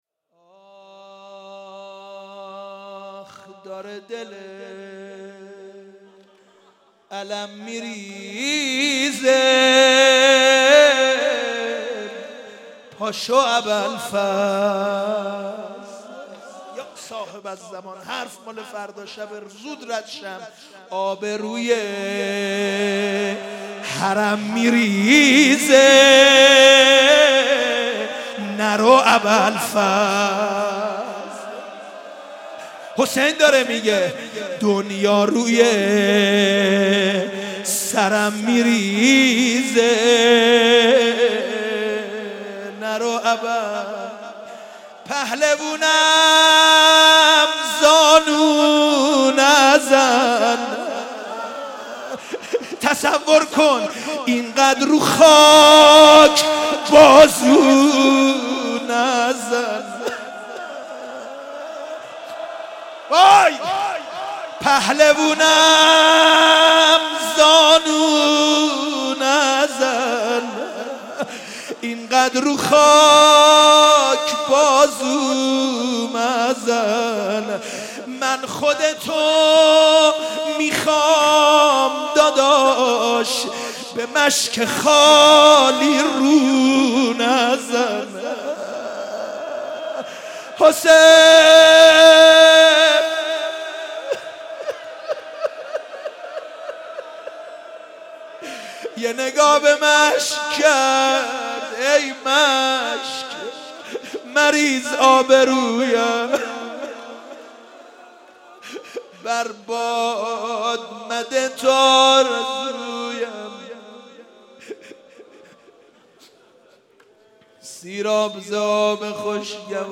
روضه و نوحه شب هشتم محرم سال 92 با نوای حداحان اهل بیت
12 دل علم میریزه پاشو ابوالفضل (مناجات)